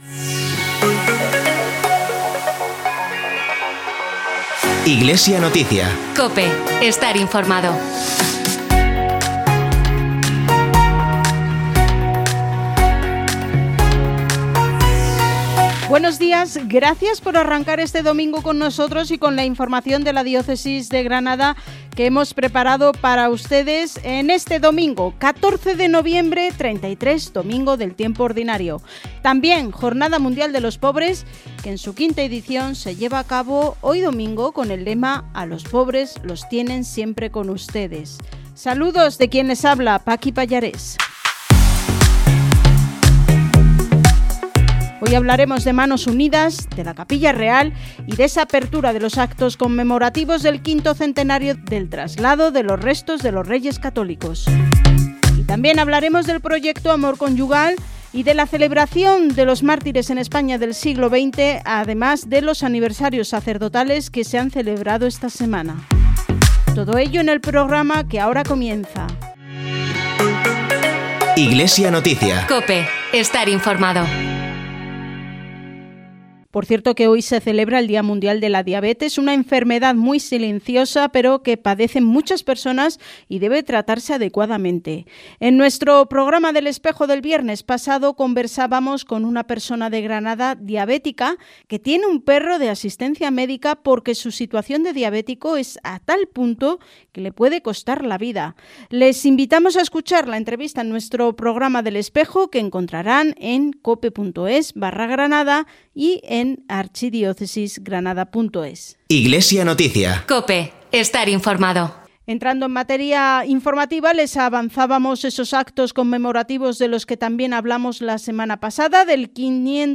Programa emitido en COPE Granada y Motril el domingo 14 de noviembre de 2021, a las 09:45 horas.